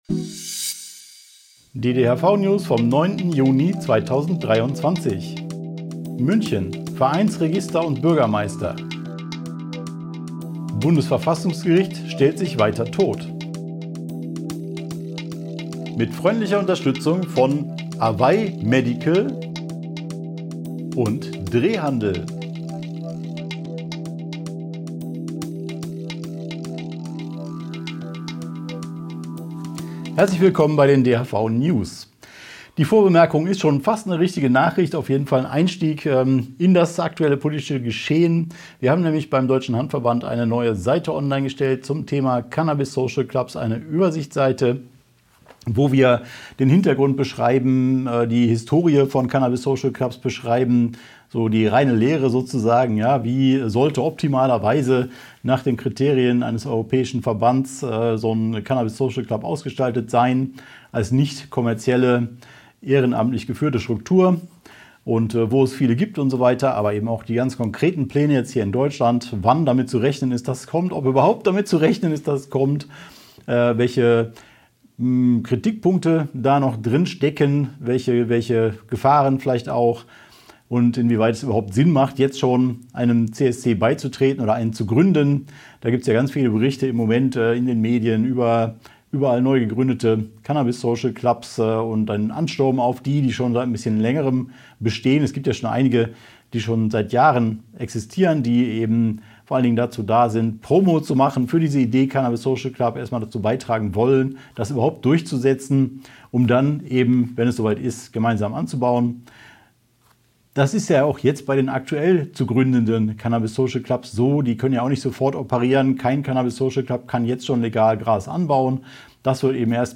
| DHV-Video-News #381 Die Hanfverband-Videonews vom 09.06.2023 Die Tonspur der Sendung steht als Audio-Podcast am Ende dieser Nachricht zum downloaden oder direkt hören zur Verfügung.